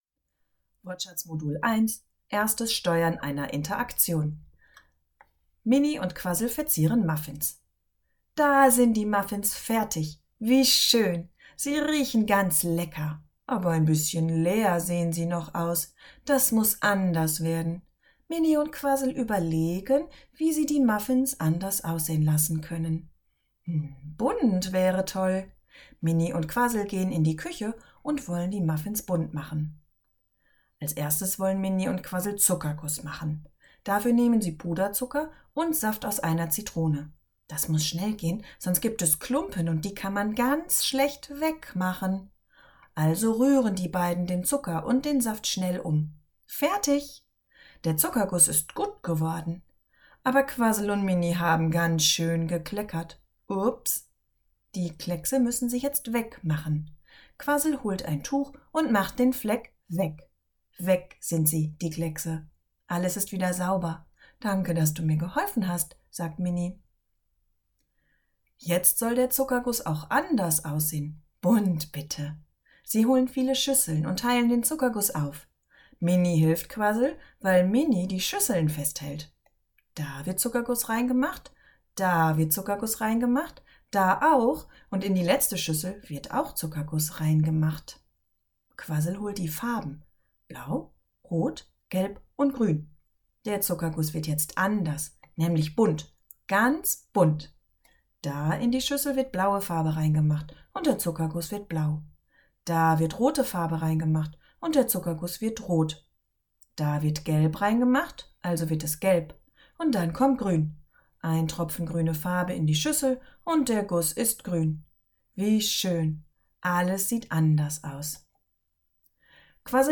Die Aufnahmen sind mit viel Intonation und wörtlicher Rede eingesprochen, damit die Kinder ein lebhaftes Hörerlebnis haben.